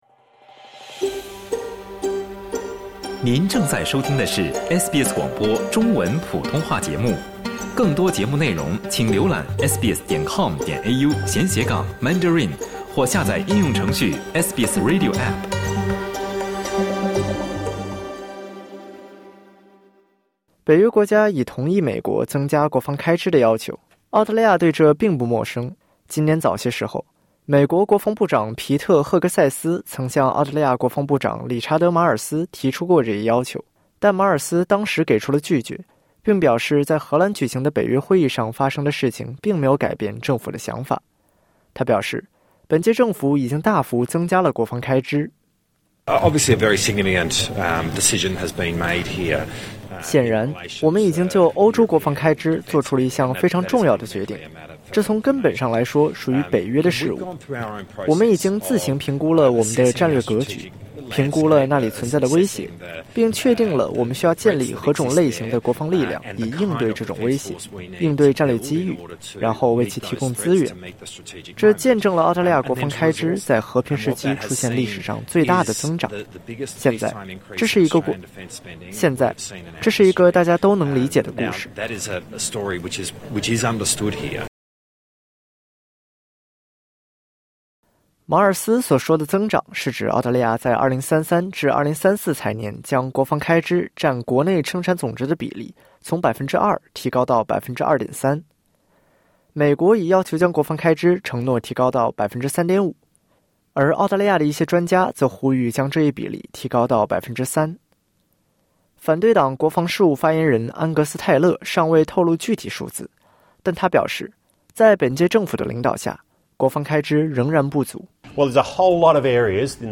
即便北约国家在荷兰举行的会议上同意将国防开支提高到美国政府要求的水平，但澳大利亚态度坚决，他们不会这样做（点击音频，收听完整报道）。